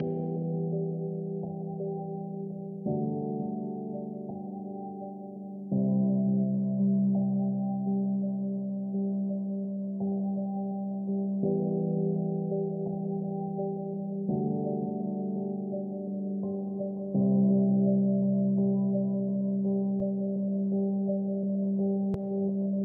洛菲钢琴和弦 84 Bpm
不知道这是什么调，因为我投了弯，但我想可能是Bmaj或Amaj吧。
标签： 84 bpm Chill Out Loops Piano Loops 3.85 MB wav Key : Unknown
声道立体声